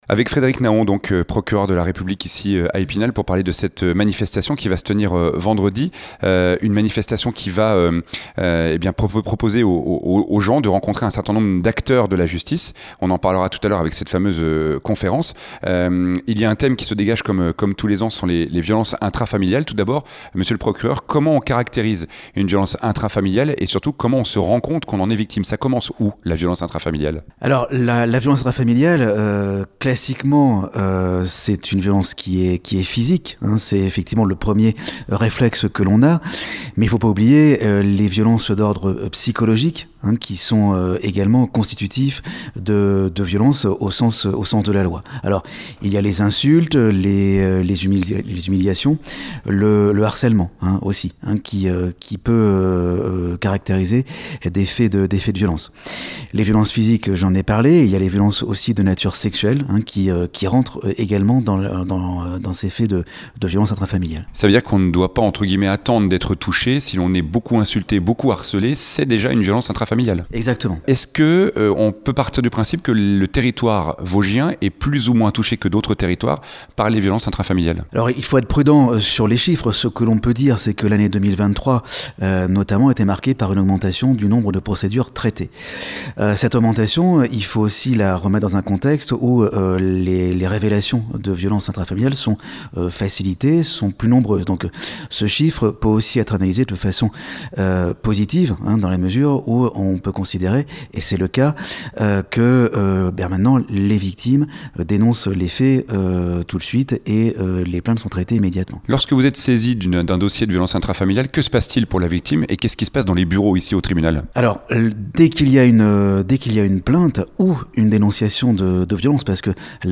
A cette occasion, nous avons interrogé le Procureur de la République d’Epinal, Frédéric Nahon sur les violences intrafamiliales dans les Vosges.